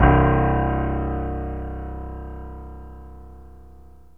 PIANO 0011.wav